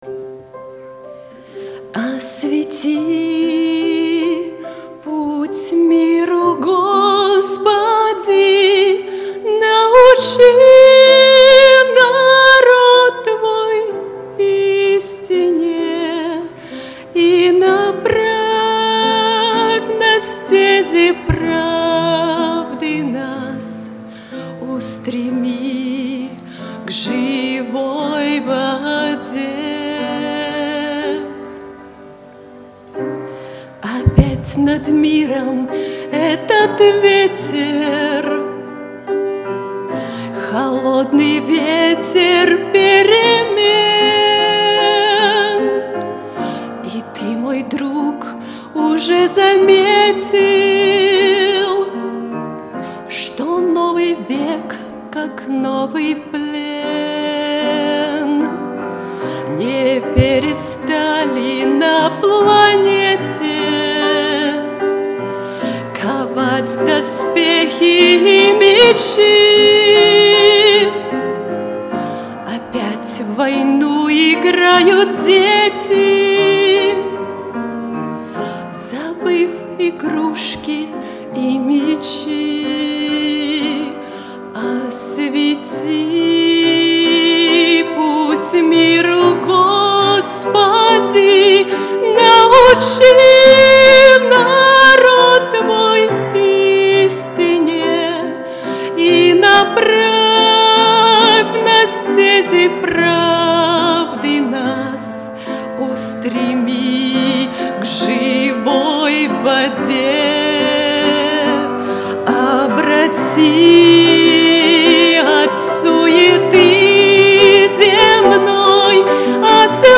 on 2014-07-08 - Фестиваль христианской музыки и песни